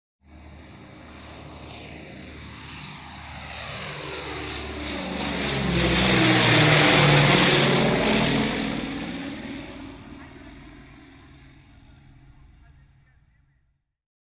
AIRCRAFT PROP SINGLE ENGINE: EXT: Fly by medium speed. Engine doppler and voices at end.